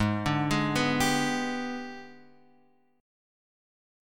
G#mM7b5 chord